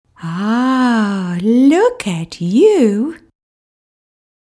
Baby Talk
• Contoured by the rising and falling of the pitch and rhythm - which babies identify as parents expressing their affection (for example click
prosody.mp3